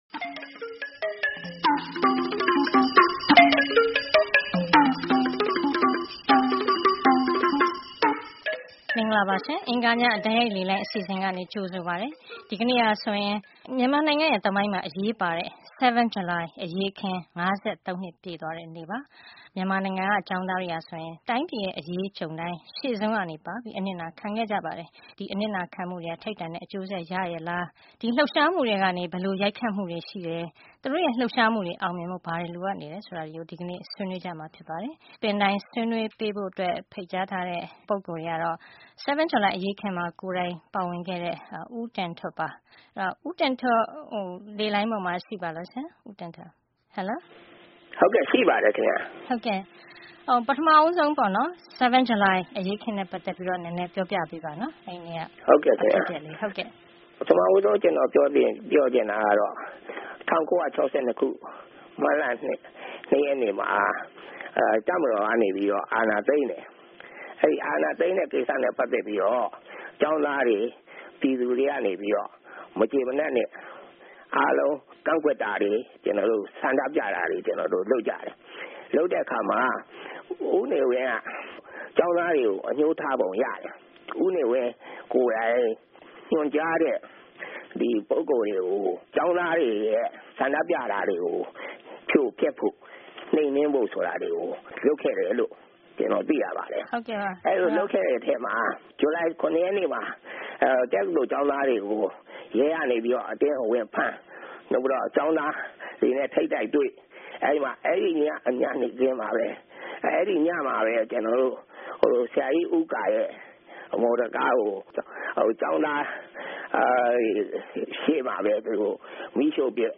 Tuesday Call in Show 07-07-15
တိုက်ရိုက်လေလှိုင်း ဆွေးနွေးခန်း (07-07-15)